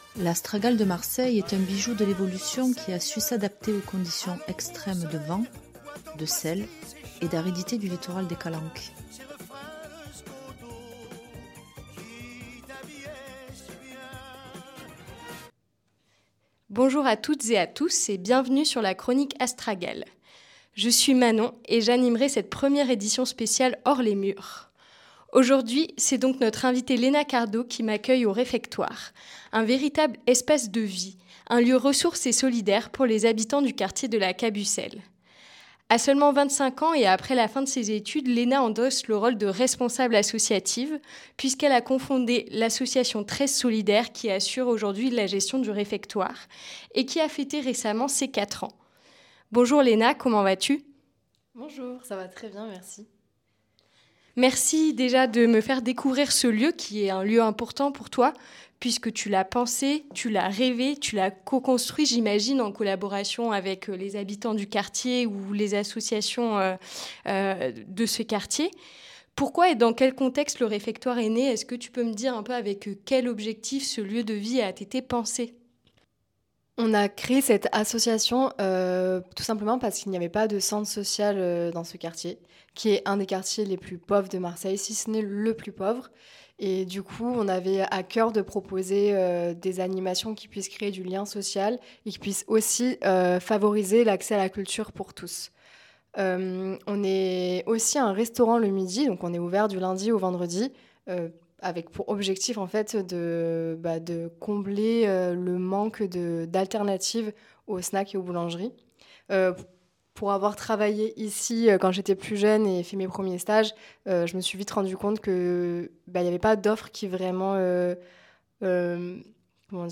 Pour cette première édition hors les murs